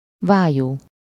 Ääntäminen
Synonyymit mangeoire auget oiseau sarcophage nau Ääntäminen France: IPA: [oʒ] Haettu sana löytyi näillä lähdekielillä: ranska Käännös Ääninäyte 1. vályú Suku: f .